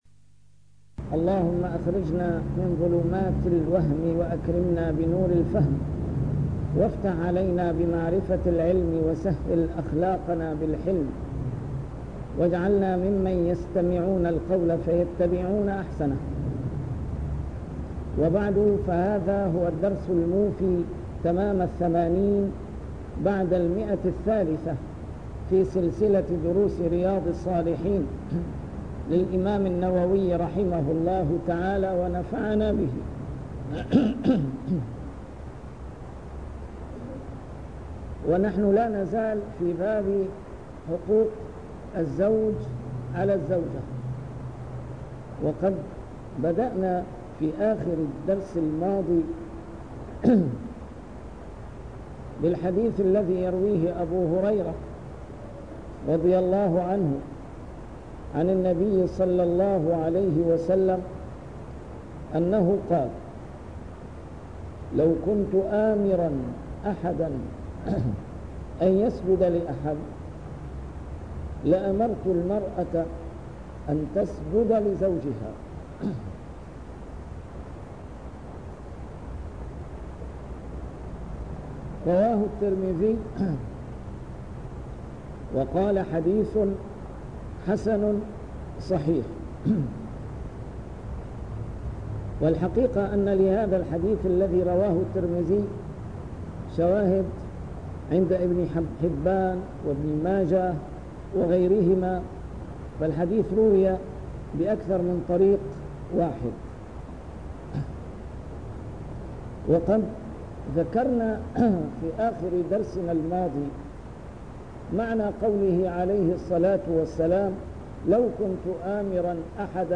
شرح كتاب رياض الصالحين - A MARTYR SCHOLAR: IMAM MUHAMMAD SAEED RAMADAN AL-BOUTI - الدروس العلمية - علوم الحديث الشريف - 380- شرح رياض الصالحين: حق الزوج على المرأة